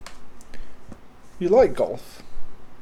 golf-falling-intonation.mp3